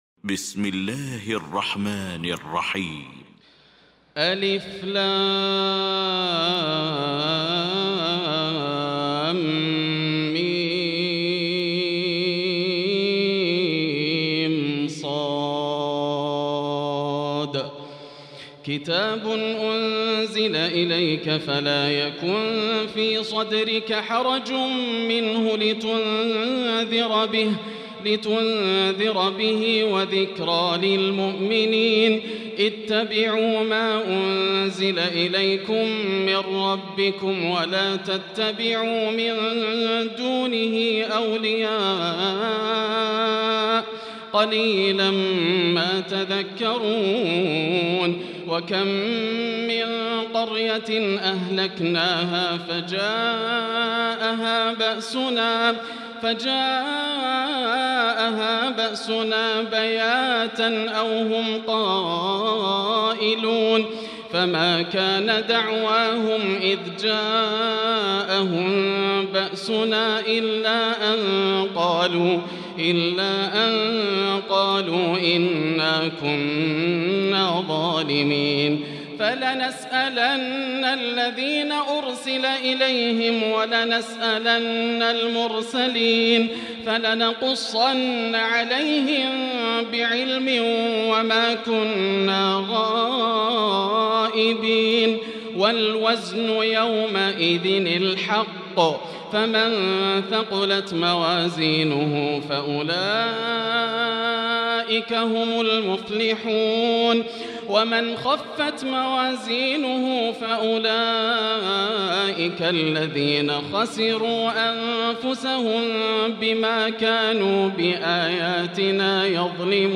المكان: المسجد الحرام الشيخ: معالي الشيخ أ.د. بندر بليلة معالي الشيخ أ.د. بندر بليلة فضيلة الشيخ عبدالله الجهني فضيلة الشيخ ياسر الدوسري الأعراف